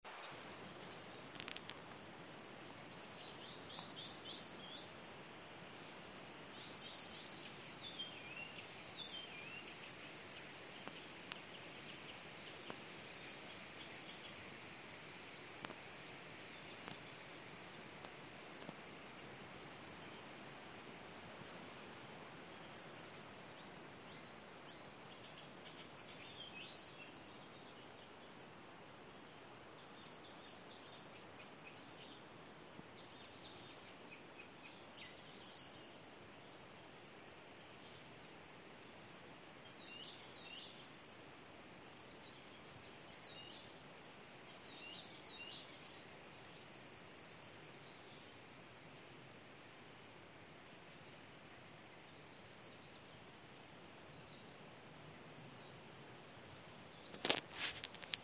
пересмешка, Hippolais icterina
СтатусПоёт
Примечанияceru, ka trāpīju. ieraksti gan klusi